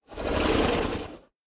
Minecraft Version Minecraft Version snapshot Latest Release | Latest Snapshot snapshot / assets / minecraft / sounds / mob / horse / zombie / idle1.ogg Compare With Compare With Latest Release | Latest Snapshot